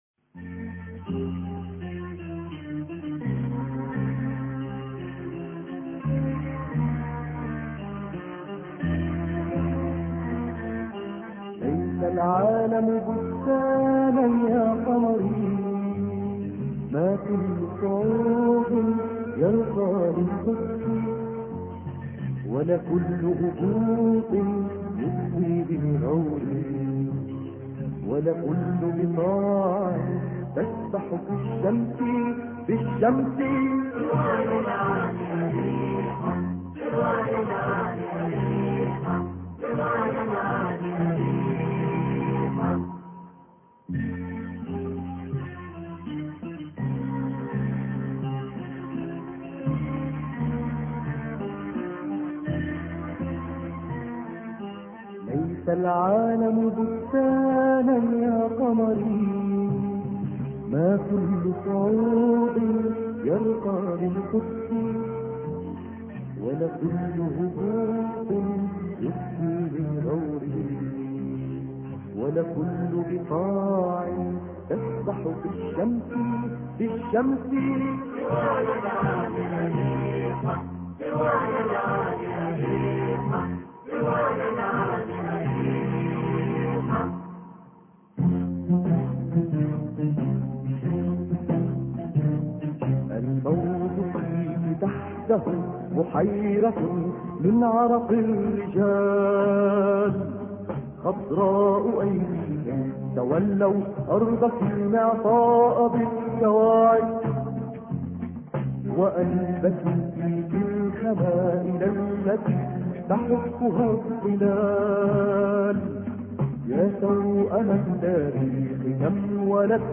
ليس لعالم بستانا يا قمري الإثنين 21 إبريل 2008 - 00:00 بتوقيت طهران تنزيل الحماسية شاركوا هذا الخبر مع أصدقائكم ذات صلة الاقصى شد الرحلة أيها السائل عني من أنا..